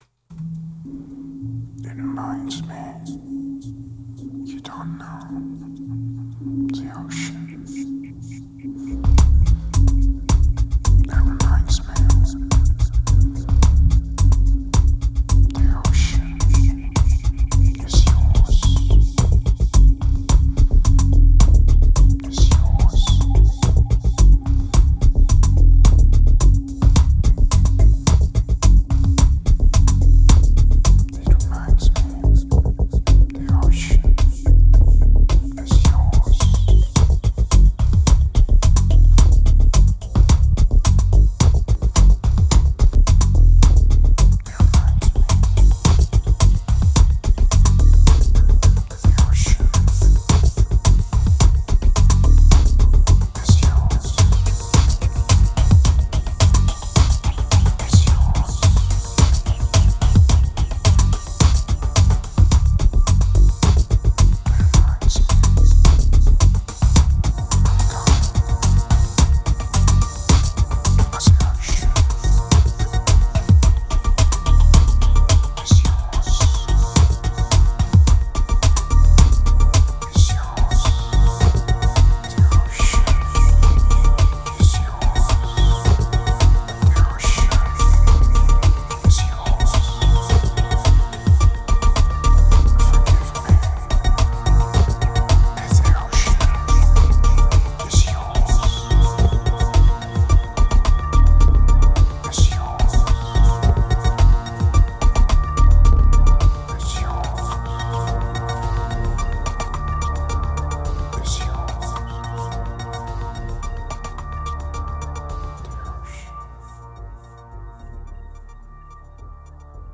2169📈 - -49%🤔 - 108BPM🔊 - 2010-03-03📅 - -365🌟